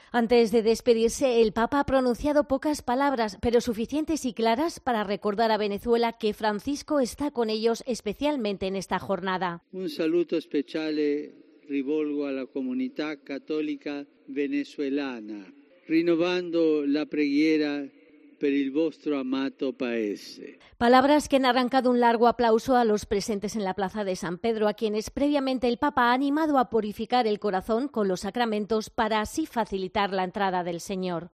El Papa Francisco renovó este domingo su oración por la "amada" Venezuela, en un breve mensaje dirigido a la comunidad católica de ese país latinoamericano presente en Italia y pronunciado tras el rezo del Ángelus dominical.
"Un saludo especial lo dirijo a la comunidad católica venezolana en Italia, renovando la oración por vuestro amado país", señaló el pontífice desde la ventana del Palacio Apostólico ante los fieles de la plaza de San Pedro.